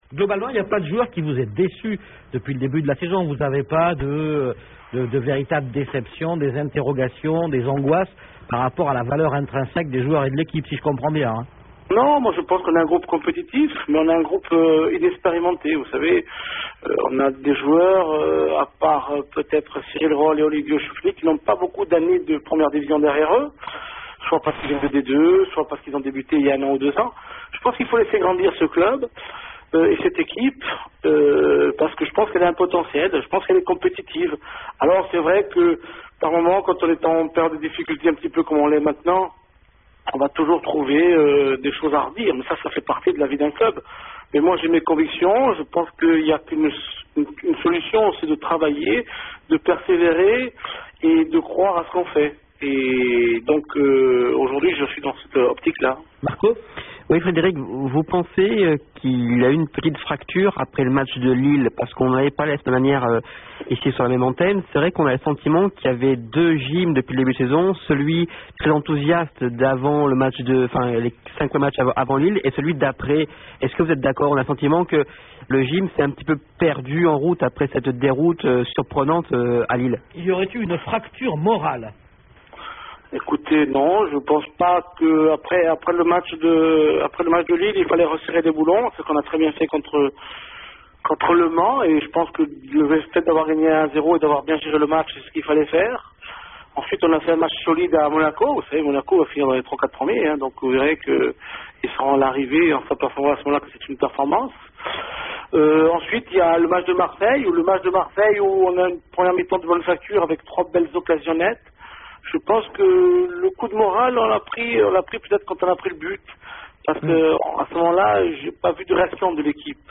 Lors d’une récente émission radiophonique locale sur le football, l’entraîneur de l’OGC Nice, Frédéric Antonetti, avait fait remarqué que les questions des auditeurs étaient bien tranchées, voire presque choisies, tant il est vrai que les premières interrogations des amateurs de FM et de l’OGC Nice n’étaient pas que des compliments envers lui-même et son travail.
D’un côté une radio qui laisse réagir les auditeurs (en les questionnant auparavant sur leur prénom et le sujet de leur appel).
Bref, tout ça pour dire qu’une interview ne reste qu’une interview…